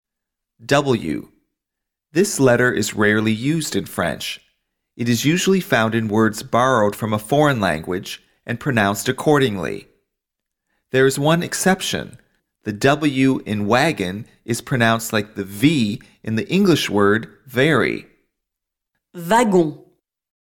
There is one exception: the “w” in “wagon” is pronounced like the “v” in the English word “very.”